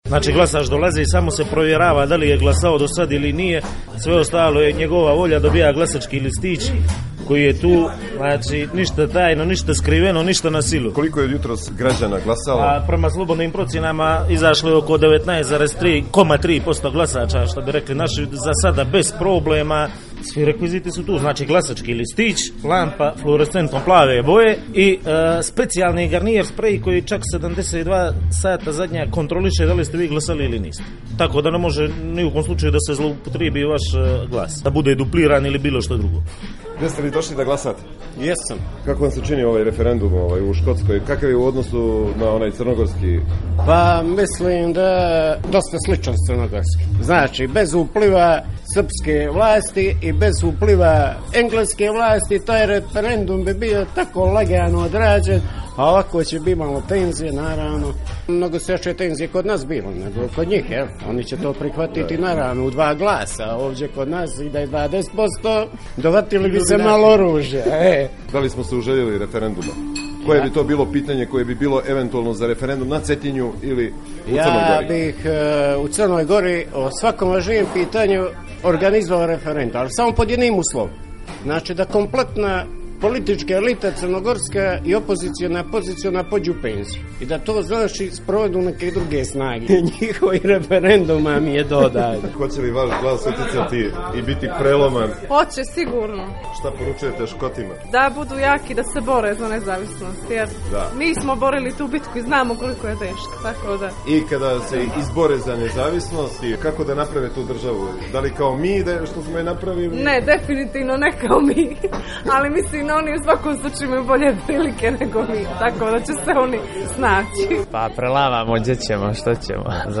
U reportaži koja slijedi čućete da li ima sličnosti sa crnogorskim referendumom o nezavisnosti, na koji način Škoti treba da urede državu, šta su motivi za glasanje za "Yes" i "No" i zašto su Cetinjani uvjek za opciju nezavisnosti?